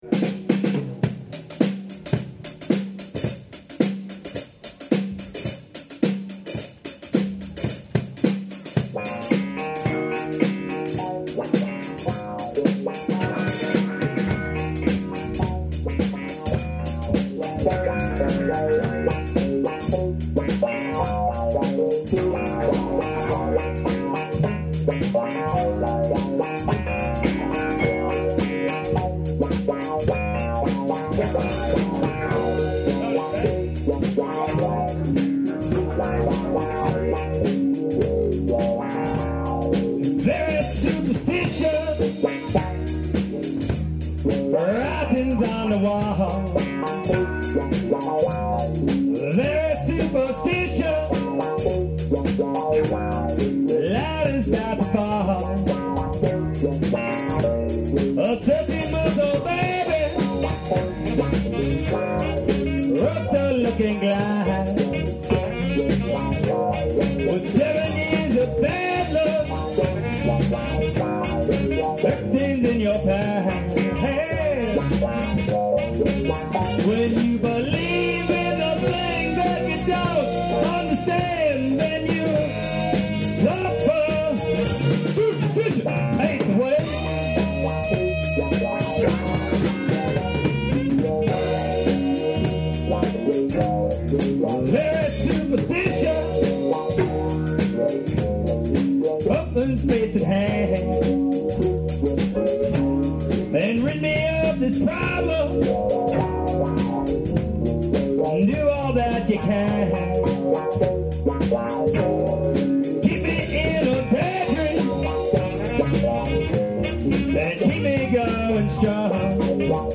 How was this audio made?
Early Show – Live at The Penn Wynne Presbyterian Church